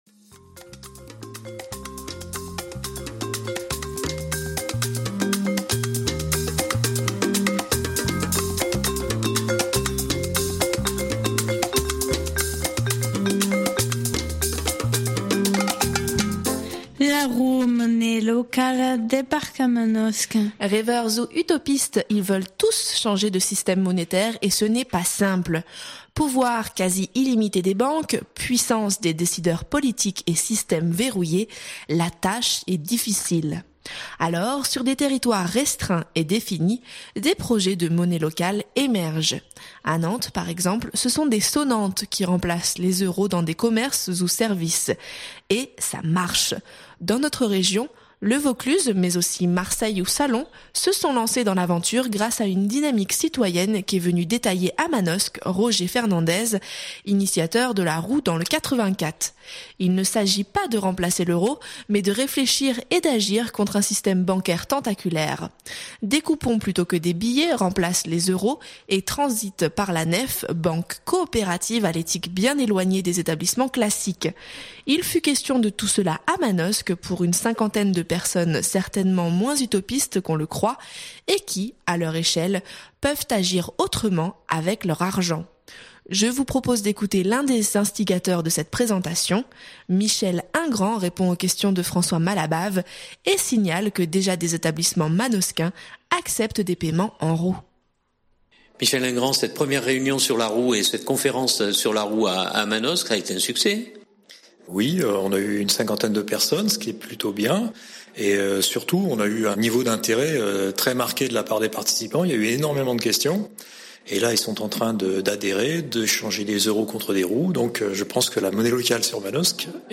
Je vous propose d’écouter l’un des instigateurs de cette présentation.